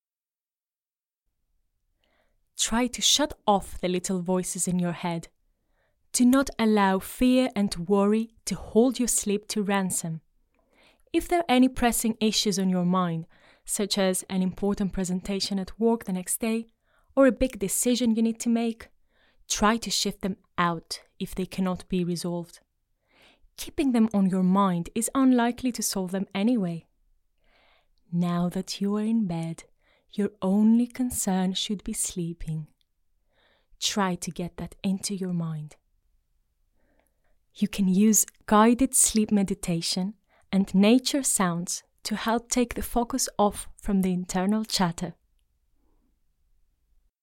Sleep guide
- Contralto